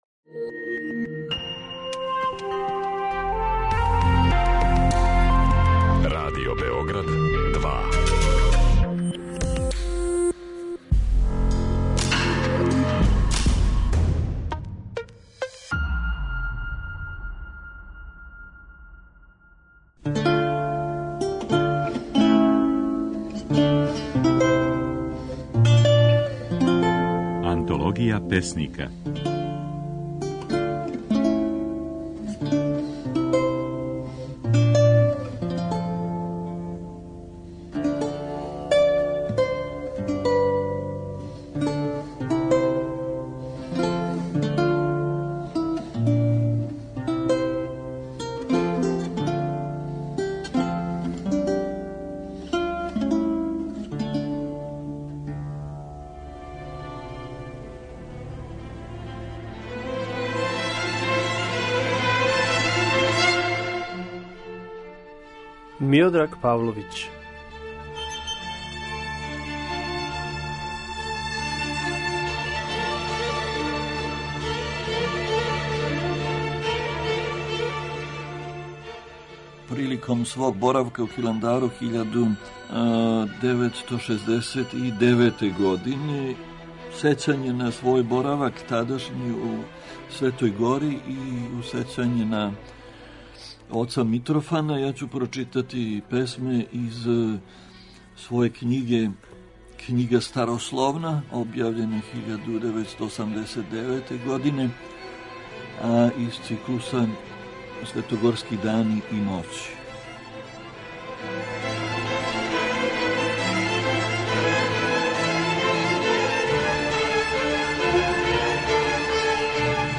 Сваког радног дана, од 24. до 28. јула, можете чути како је своје стихове говорио песник Миодраг Павловић (1928, Нови Сад - 2014, Београд).